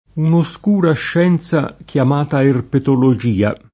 erpetologia [erpetoloJ&a] s. f. (med. «studio degli erpeti»; zool. «studio dei rettili») — es. con acc. scr. (a proposito di rettili): un’oscura scienza chiamata erpetologìa [
un oSk2ra šš$nZa kLam#ta erpetoloJ&a] (Moretti)